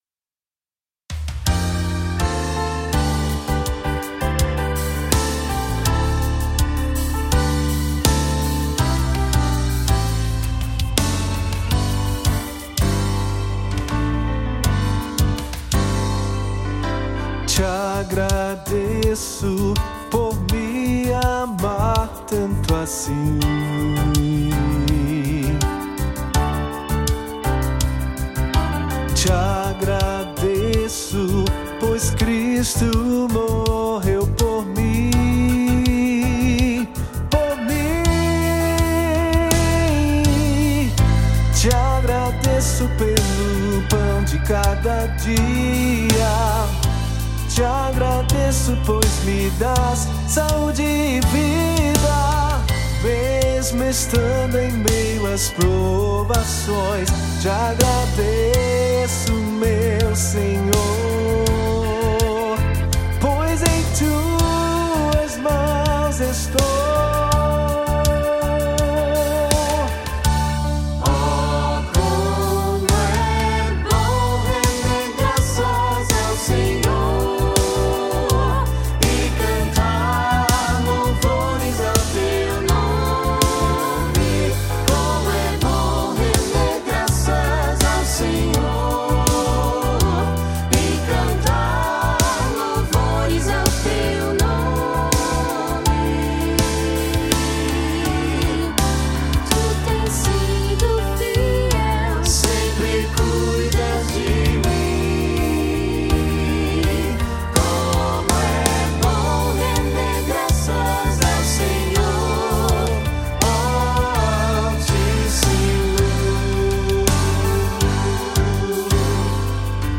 O que você receberá ao adquirir a orquestração e os VS
1. VS – Áudio / Playback
• Piano
• Bateria
• Órgão
Cordas
Madeiras